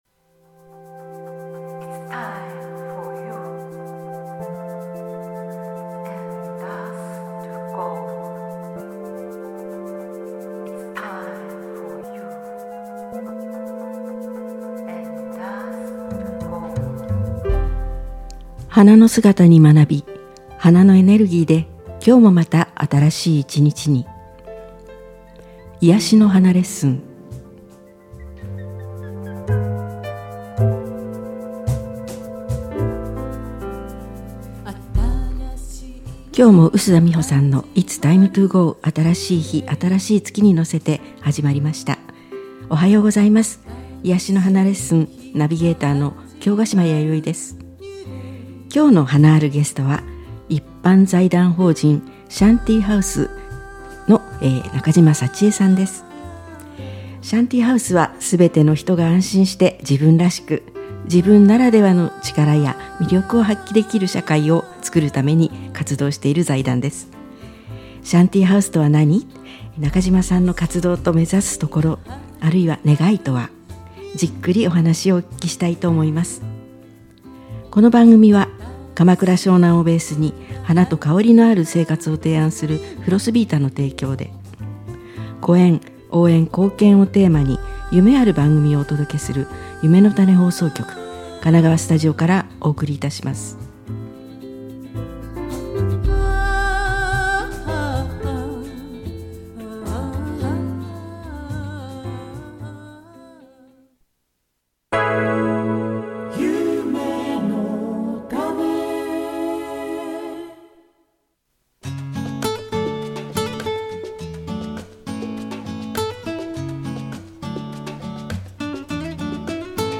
アーカイブ放送